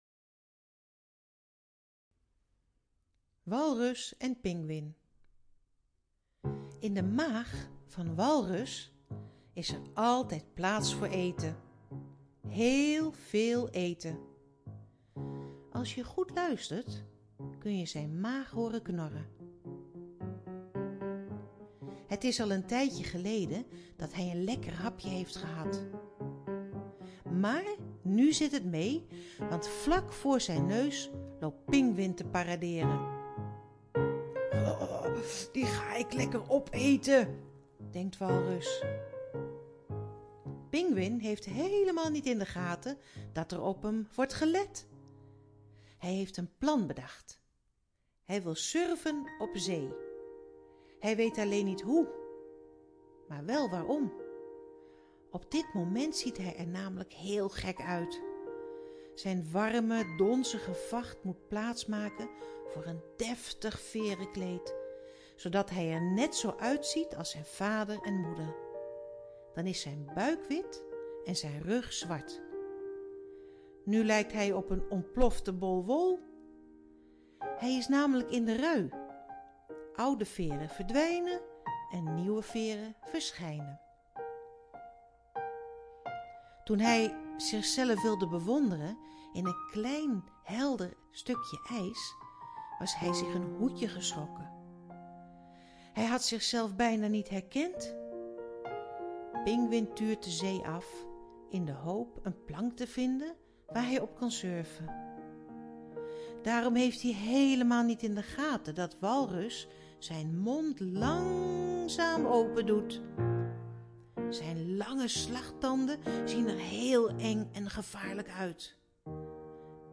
download Het Lekker Puh Luisterboek deel 2 --- Klik hierboven voor het downloaden!